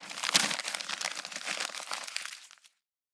crumple_paper.wav